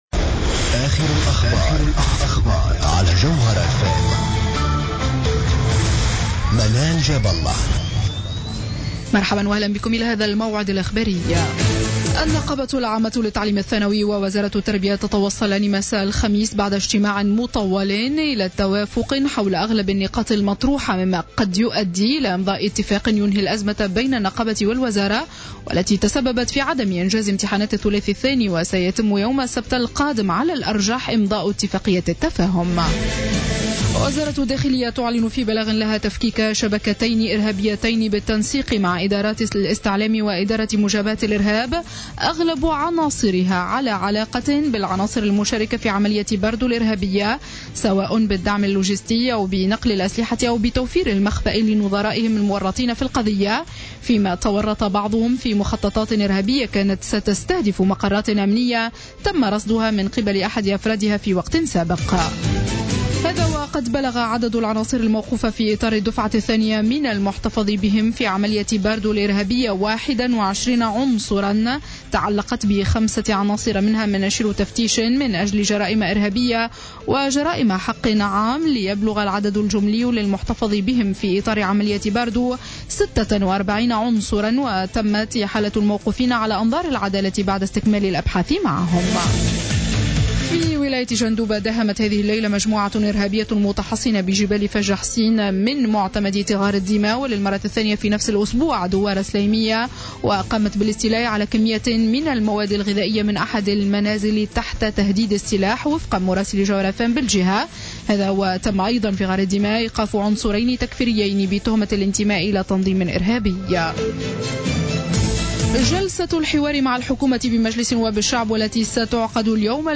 نشرة أخبار منتصف الليل ليوم الجمعة 3 أفريل 2015